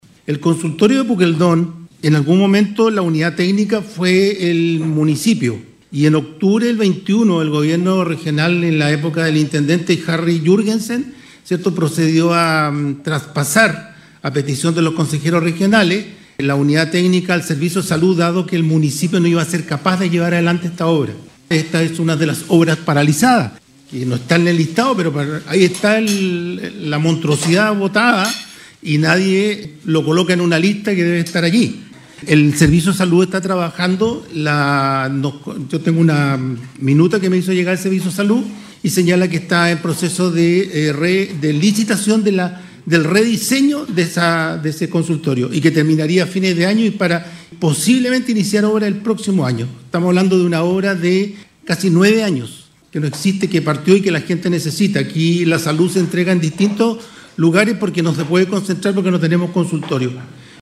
Asunto que fue planteado en la reciente sesión del Core, por el representante de Chiloé, Nelson Águila, quien planteó que a raíz de este enorme retraso se ha postergado por casi una década la oportuna atención de la salud de los habitantes de Puqueldón.